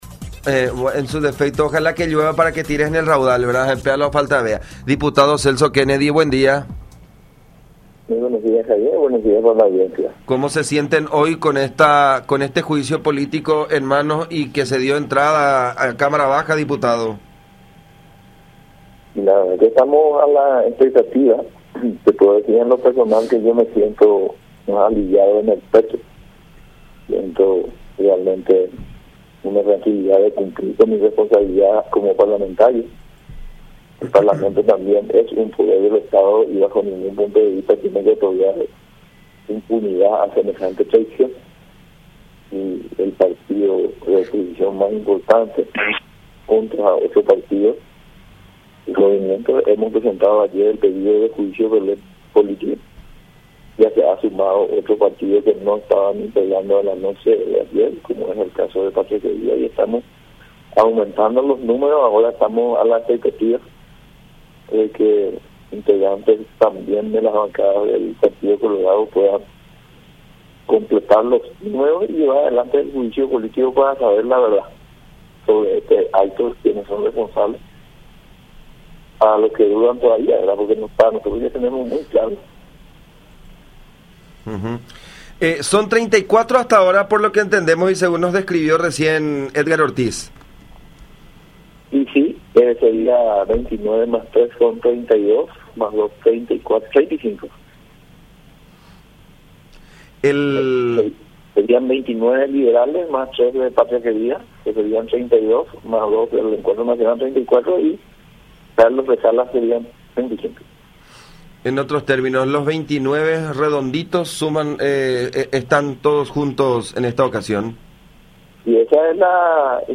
03-Celso-Kennedy-Diputado-Nacional.mp3